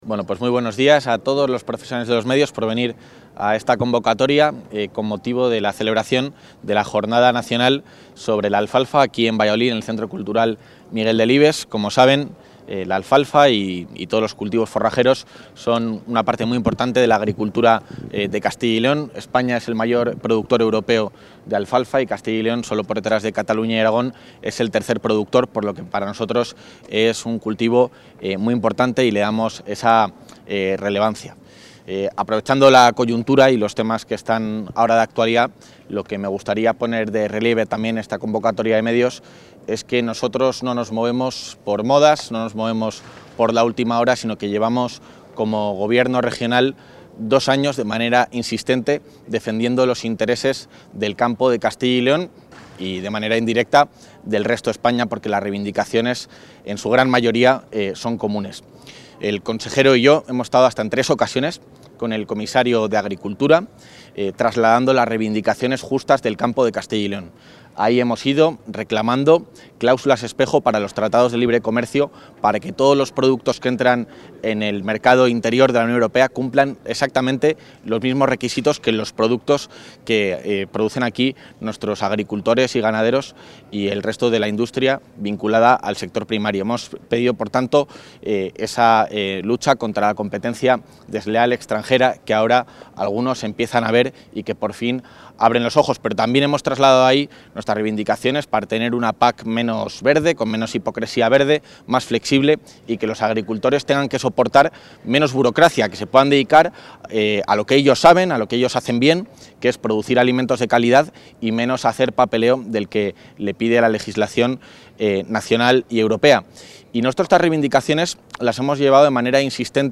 Intervención del vicepresidente de la Junta.
Galería Multimedia Intervención del vicepresidente de la Junta III Jornada Española del Cultivo de la Alfalfa III Jornada Española del Cultivo de la Alfalfa III Jornada Española del Cultivo de la Alfalfa